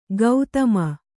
♪ gautama